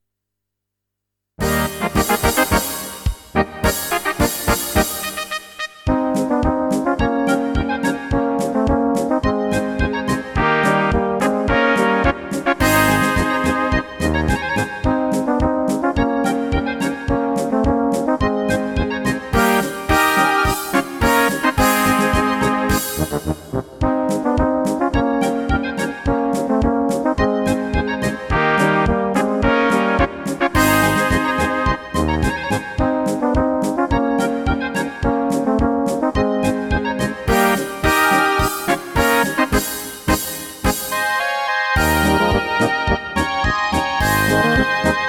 Rubrika: Národní, lidové, dechovka
- polka
Karaoke